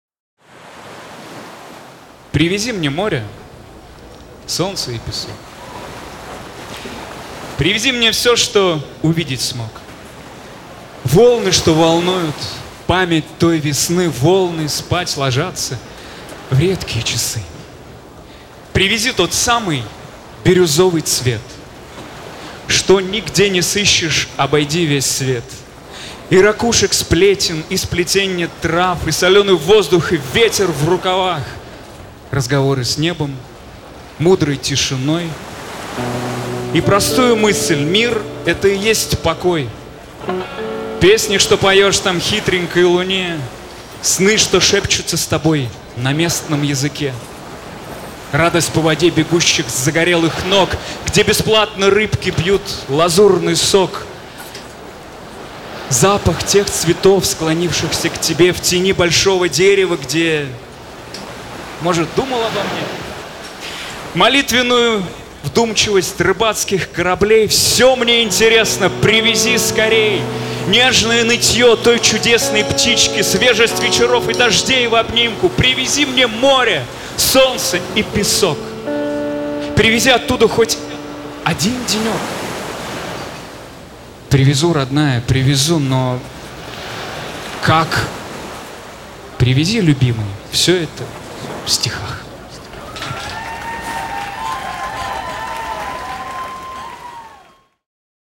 [Live]